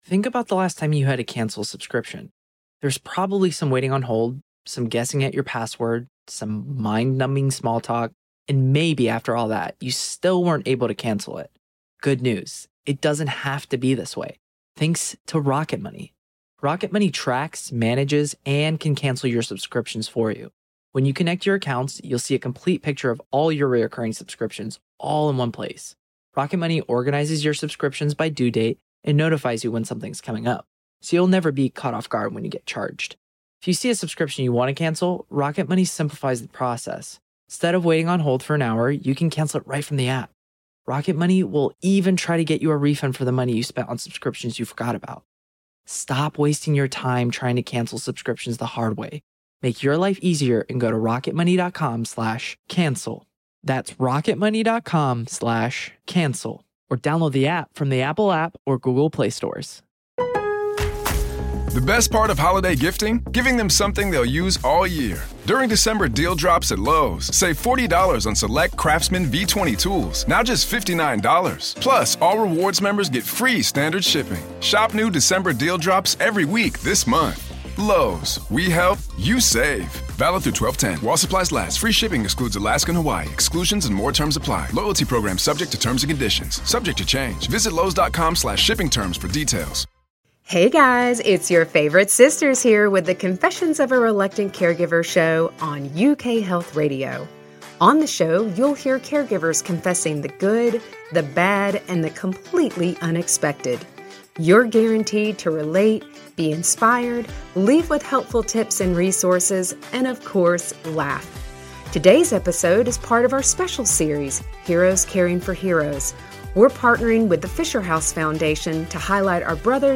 We are a trio of sisters supporting our mom who is living with Parkinson's disease, and a husband who survived cancer. We share the good, the bad, and the completely unbelievable of our caregiving journey.